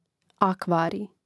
àkvārij akvarij